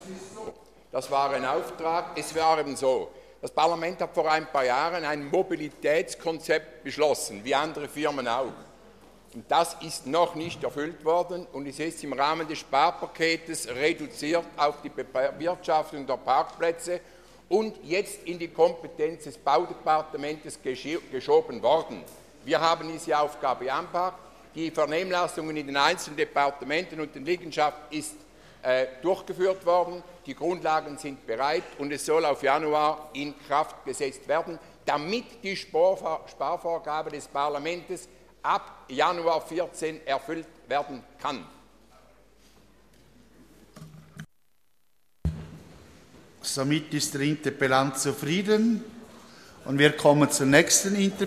18.9.2013Wortmeldung
Session des Kantonsrates vom 16. bis 18. September 2013